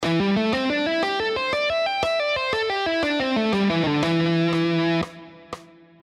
Here are the five positions of the A minor pentatonic scale, all played in triplets:
Guitar Triplets Exercise 4 – A Minor Pentatonic Position 4: